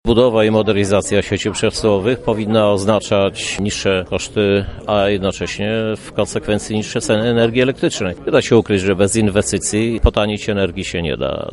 Dzięki tym inwestycjom powinny zmniejszyć się koszty produkcji a w konsekwencji niższe ceny energii elektrycznej – uważa prezydent miasta Lublin Krzysztof Żuk.